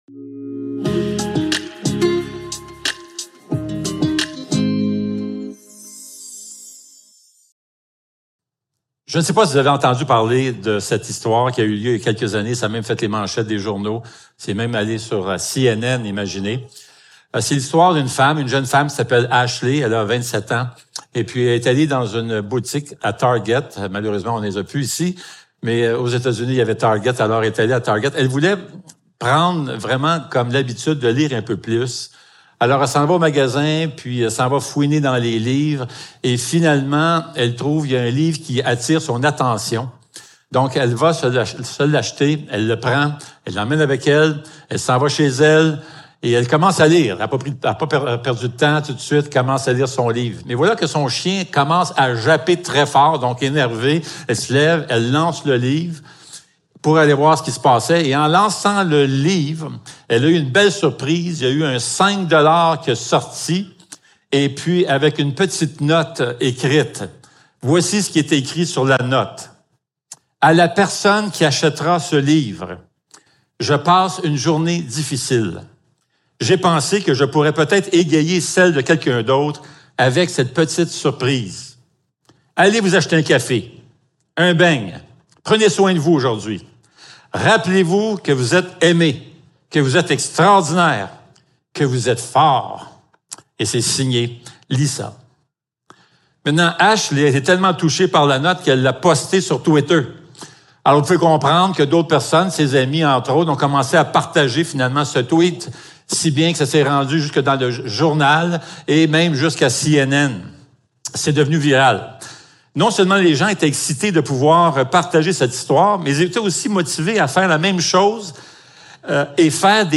Galates 5.22 Service Type: Célébration dimanche matin Description